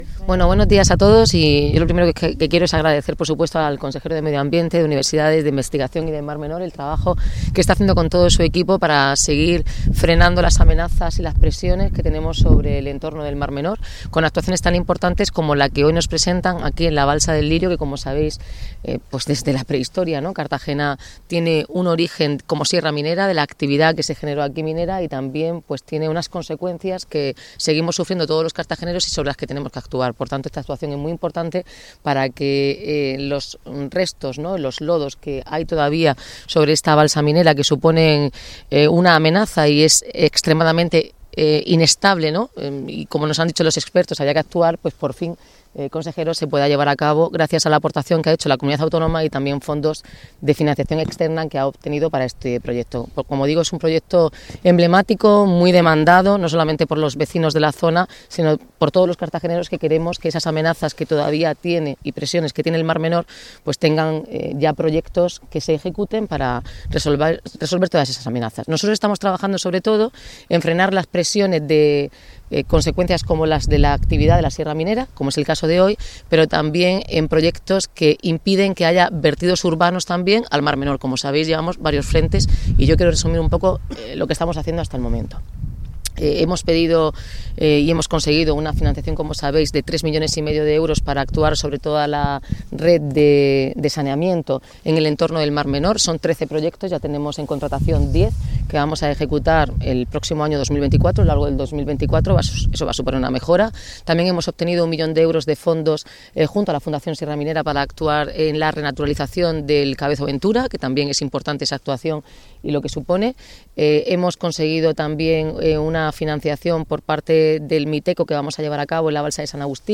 Enlace a Declaraciones de Noelia Arroyo y Juan María Vázquez
Así lo han asegurado tanto la alcaldesa como el consejero de Medio Ambiente, Universidades, Investigación y Mar Menor, Juan María Vázquez, con motivo de su visita este miércoles 20 de diciembre a las obras que se encuentran en plena ejecución, a la que también ha ido el concejal del área de Litoral y Sanidad, Gonzalo López.